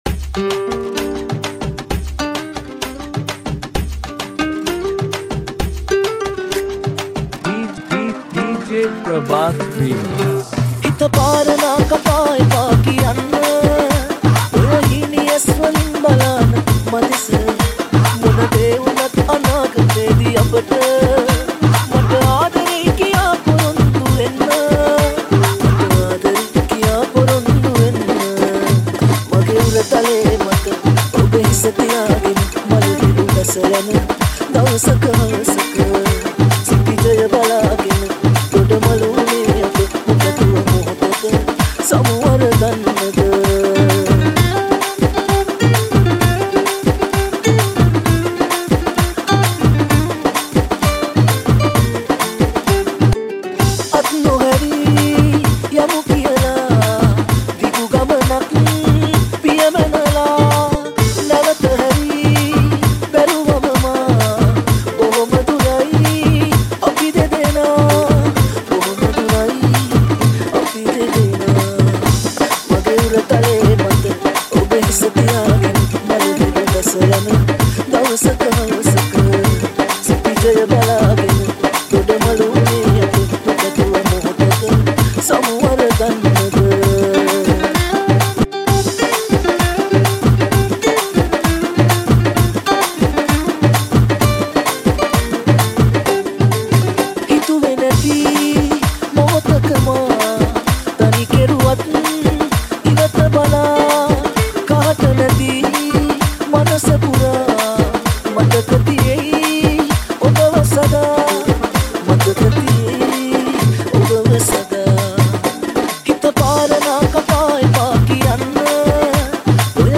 High quality Sri Lankan remix MP3 (2.6).